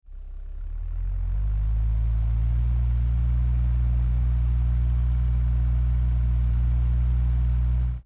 Fan
Fan.mp3